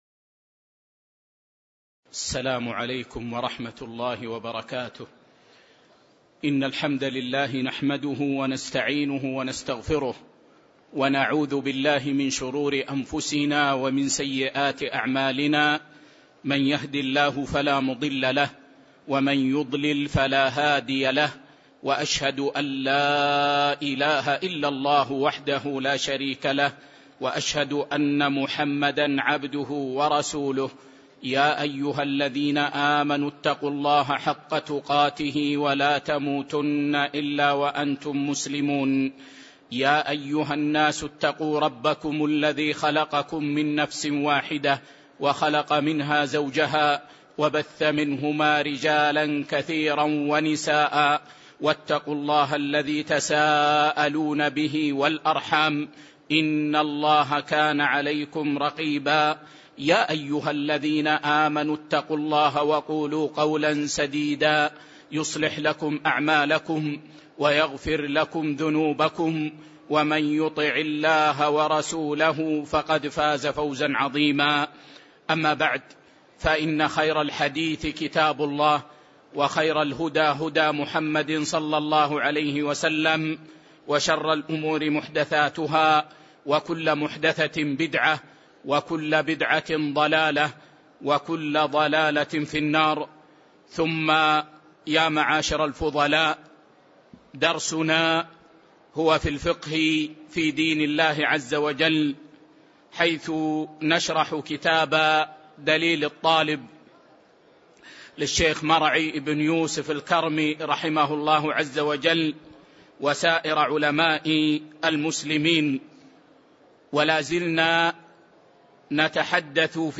تاريخ النشر ١٨ صفر ١٤٤١ هـ المكان: المسجد النبوي الشيخ